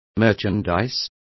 Complete with pronunciation of the translation of merchandise.